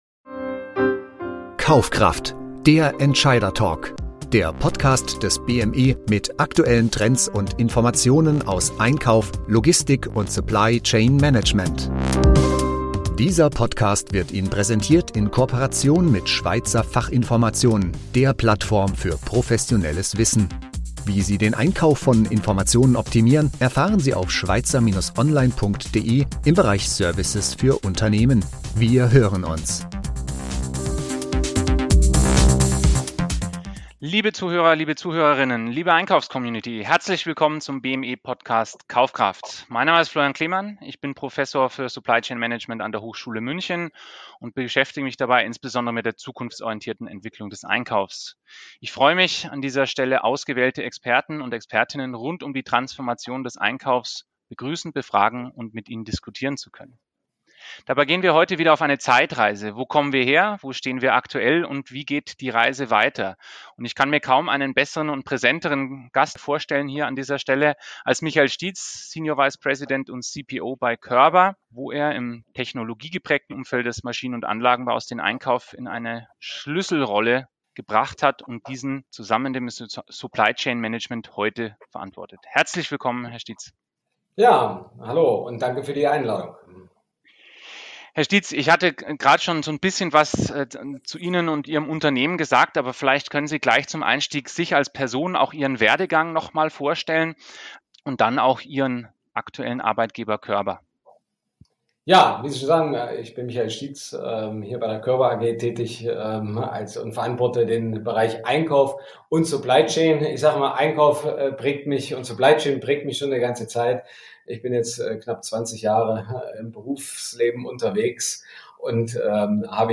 zu einem spannenden Interview zu begrüßen. Dabei wurde insbesondere auf die Treiber des Wandels und den Druck zum Handeln im Einkauf eingegangen.